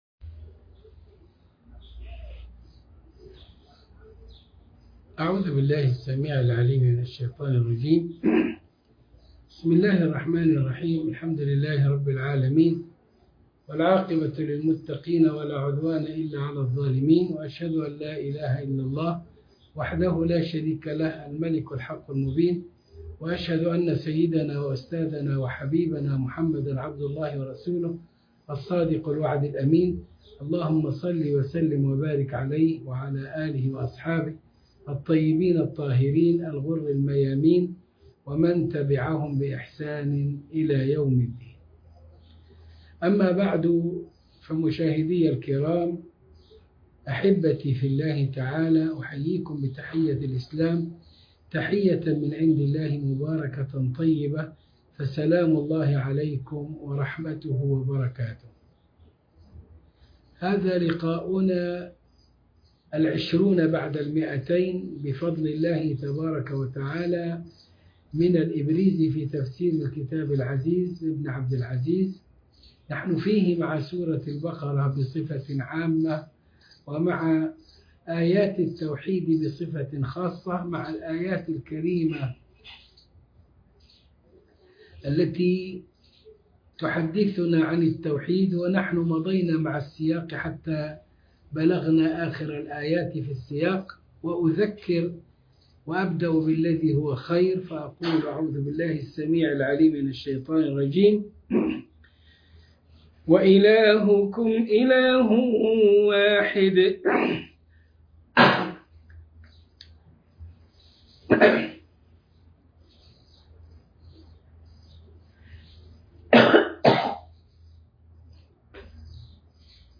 الدرس ٢٢٠ من الإبريز في تفسير الكتاب العزيز سورة البقرة الآية ١٦٦ و ما بعدها